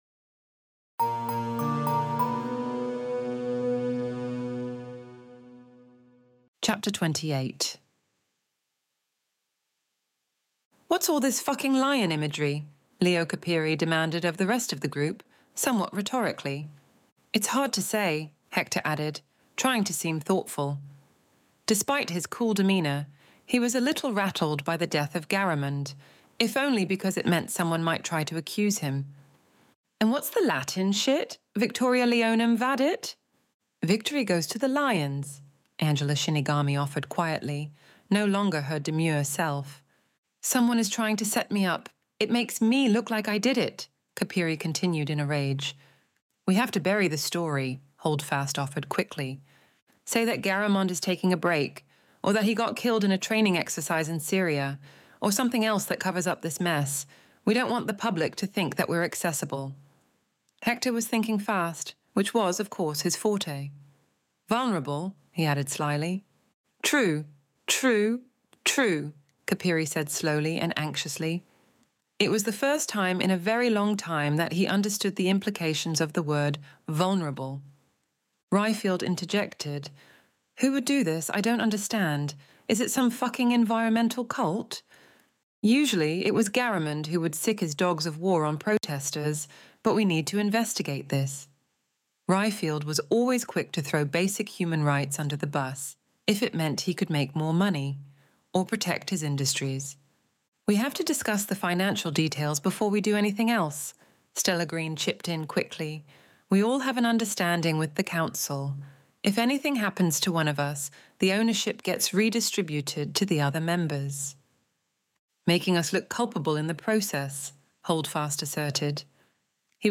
Extinction Event Audiobook Chapter 28